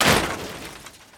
bump.mp3